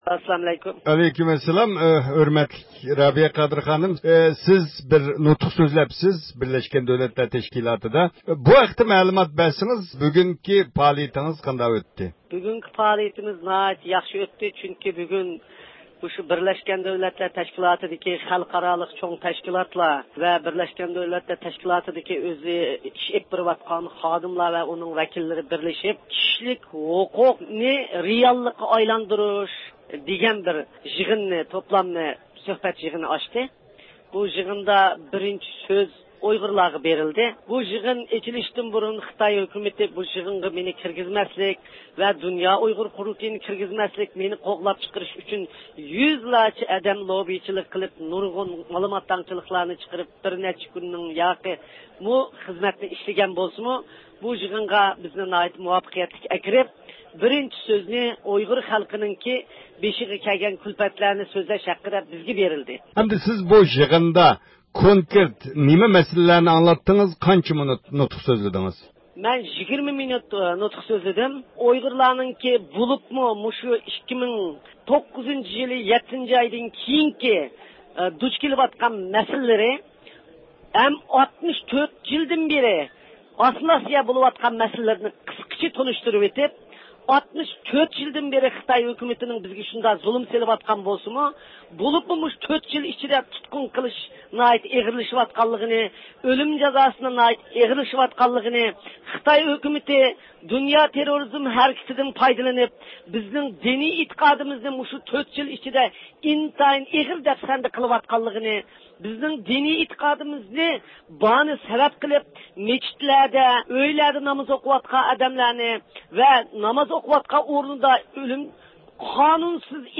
د ئۇ ق رەئىسى رابىيە قادىر خانىم ب د ت دە نۇتۇق سۆزلىدى. 2013-يىلى 25-سېنتەبىر، جەنۋە.